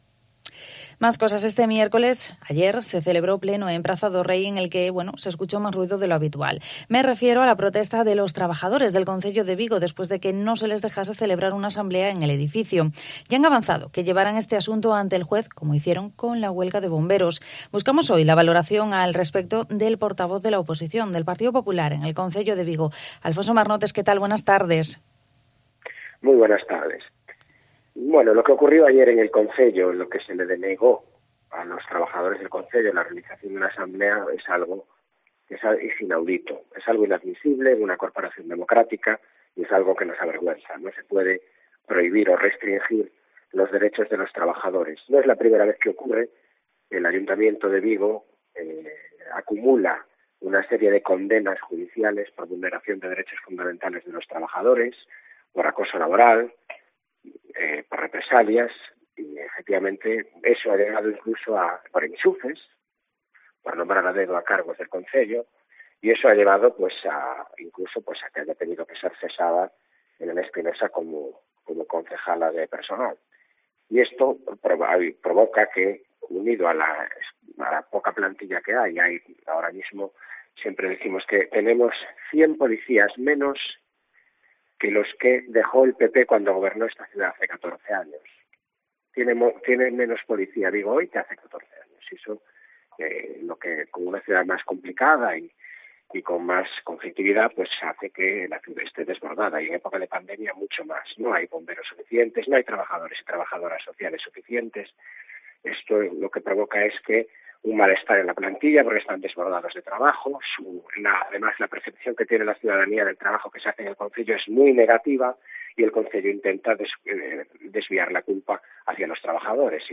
ENTREVISTA
Hablamos con el portavoz del Partido Popular, en el Concello de Vigo, Alfonso Marnotes, sobre la protesta que llevaron a cabo trabajadores del ayuntamiento este miércoles tras no dejarles celebrar una asamblea. También sobre el caso del exalcalde pedáneo de Bembrive y sobre sus propuestas culturales para potenciar Vigo.